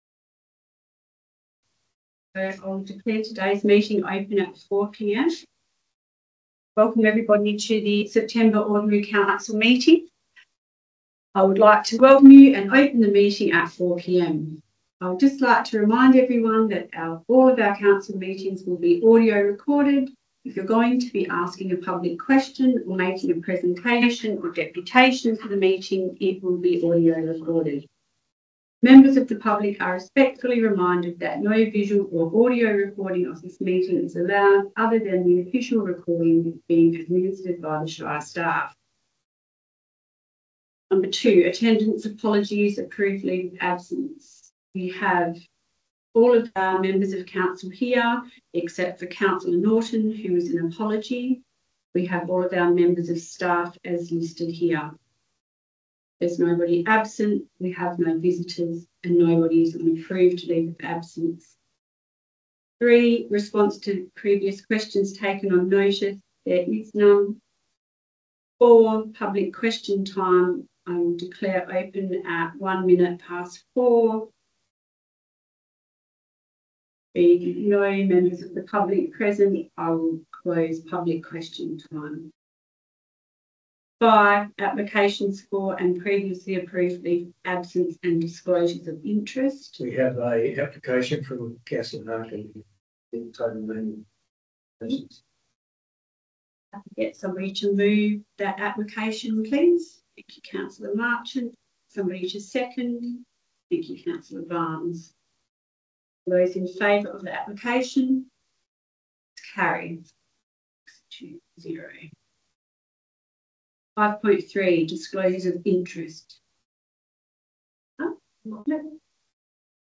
10 September 2025 Ordinary Meeting of Council » Shire of Trayning